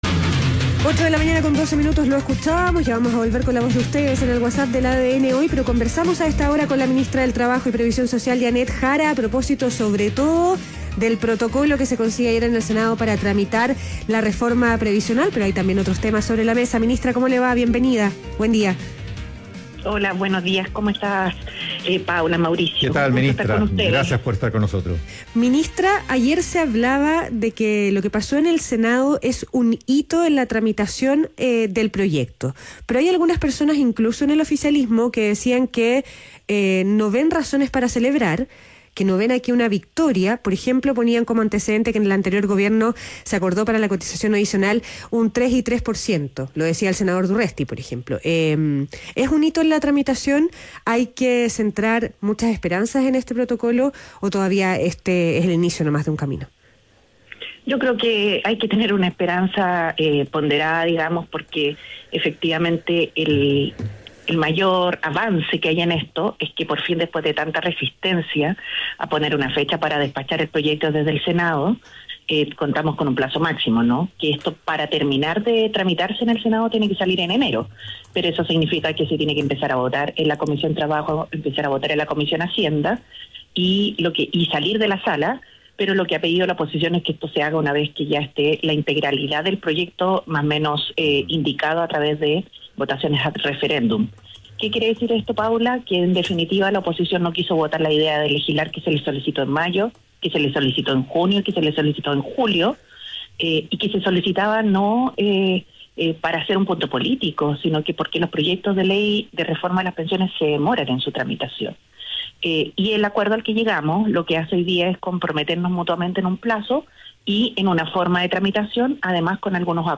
ADN Hoy - Entrevista a la ministra del Trabajo, Jeannette Jara